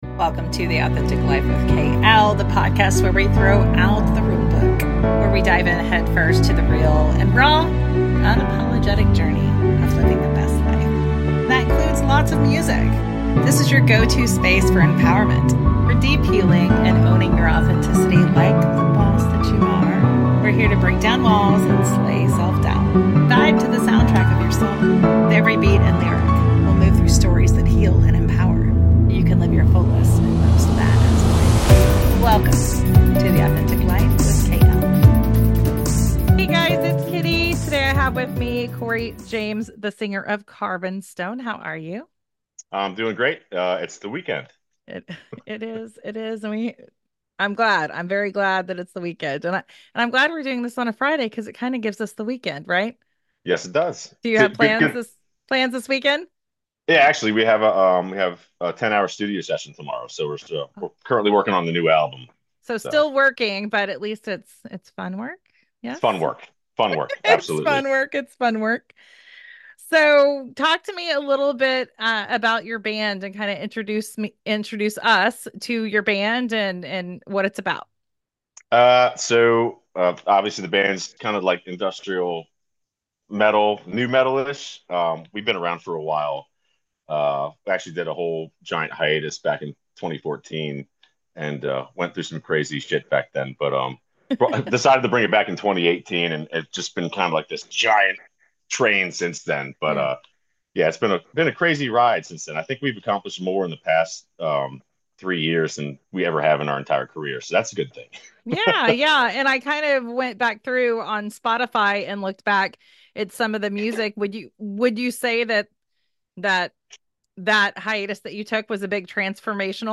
Get ready for a conversation filled with passion, authenticity, and, of course, some unforgettable tunes.